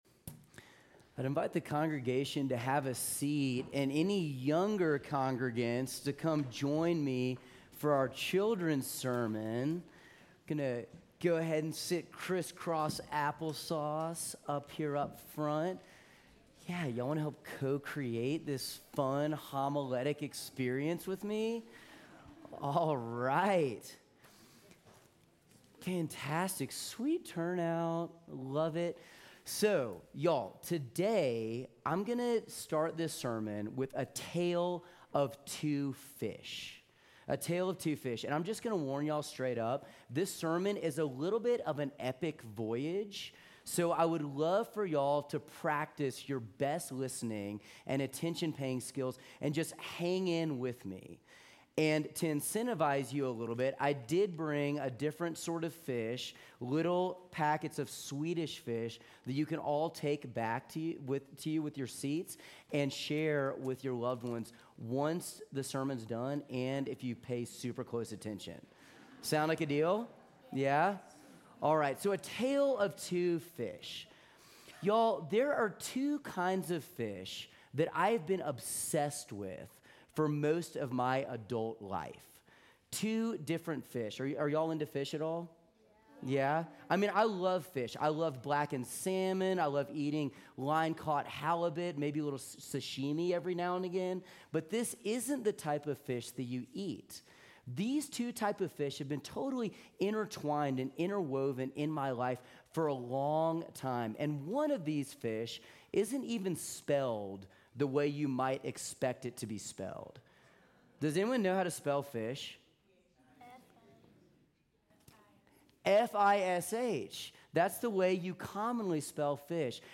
September Family Service
Sermons